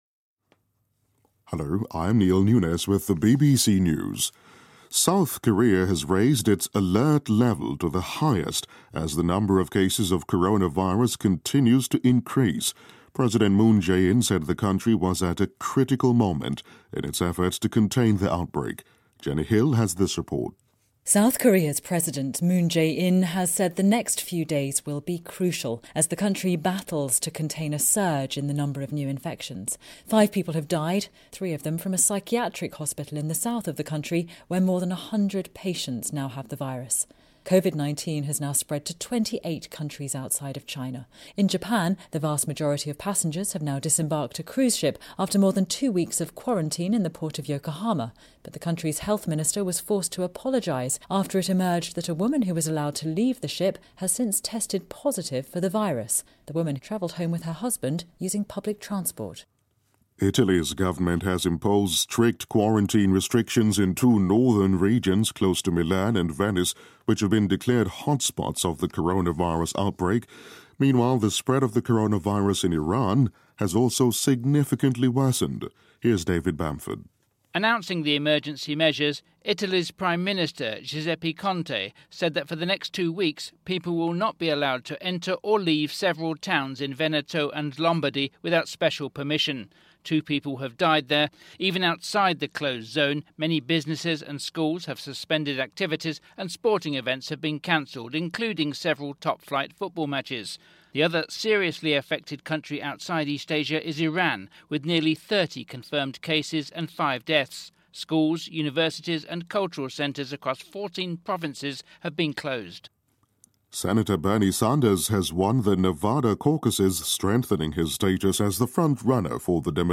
News
英音听力讲解:桑德斯赢下民主党内华达州预选